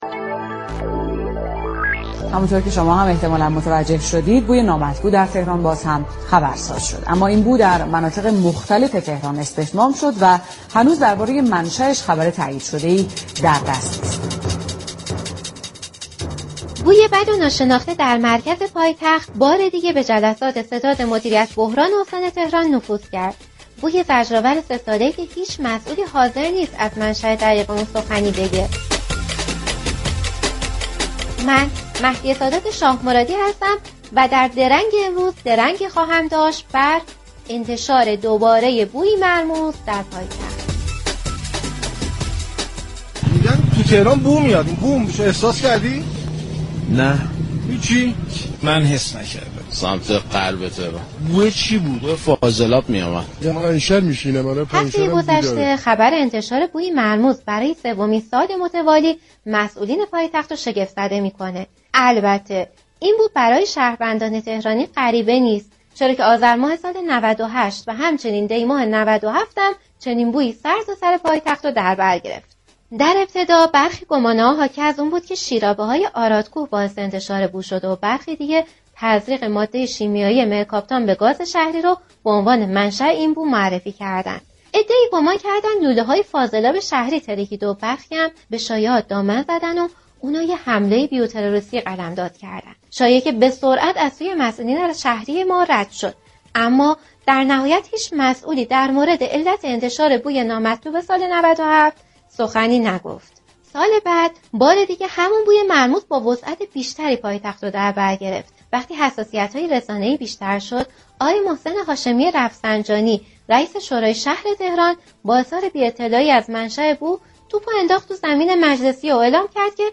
به گزارش پایگاه اطلاع رسانی رادیو تهران، مهندس رضا كرمی در گفتگو با برنامه سعادت آباد رادیو تهران درباره شناسایی منشا بوی بد شهر تهران با ذكر اینكه از فروردین سال 98 عهده دار این مسئولیت شده است گفت: سال گذشته در پی انتشار بوی بد در تهران به خرید چند دستگاه شناسایی و تشخیص بوی گاز اقدام كردیم این دستگاه ها گازهایی با بوی بسیار كم را هم شناسایی می كنند اما تا به امروز هنوز منشأ خاصی برای آن پیدا نكرده ایم.